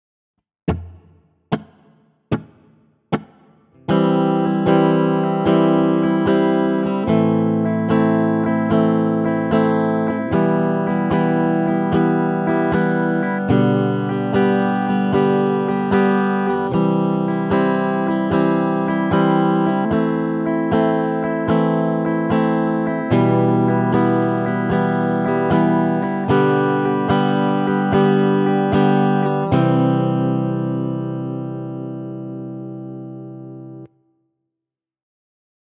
Rythme: Croche pointée - double
Audio : 4 X B(H)(B)H sur chaque accord (DO lAm MIm SOL)